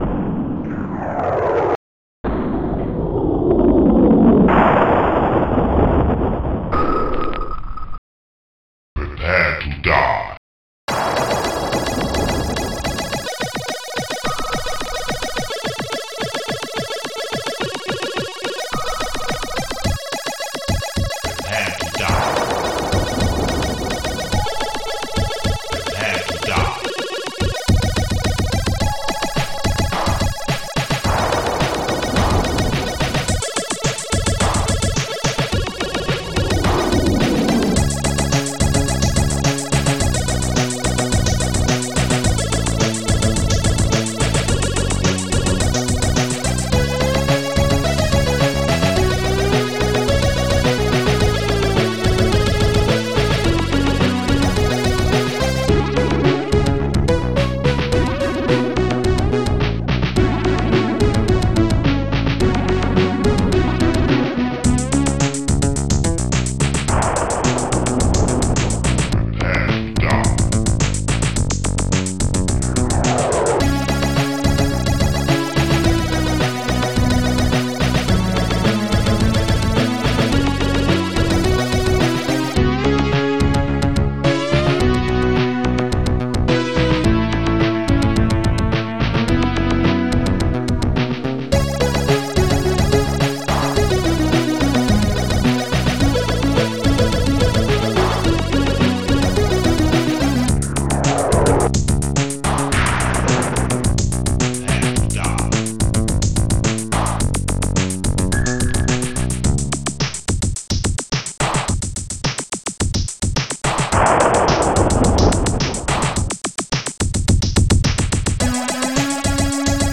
st-11:acidbassdrum2
st-11:snare18
st-11:shortstring
st-11:synbass2
st-01:hihat2